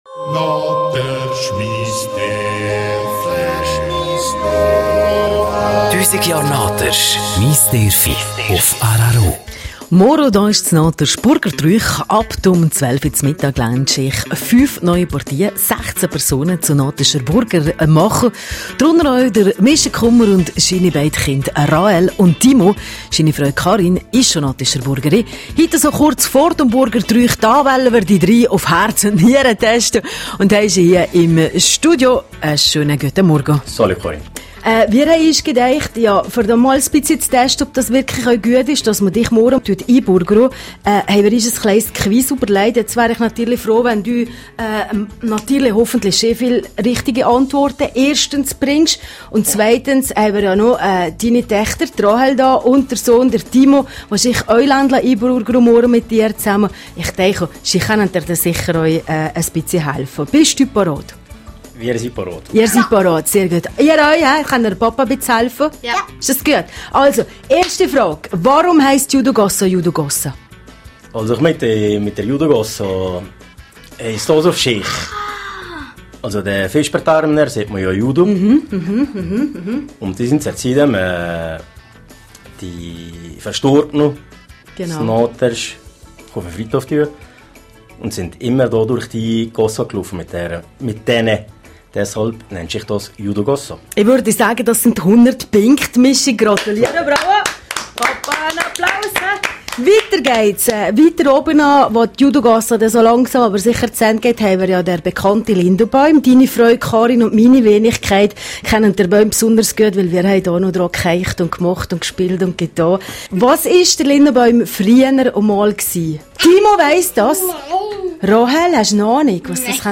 Burgertrüüch in Naters: Interview Teil 3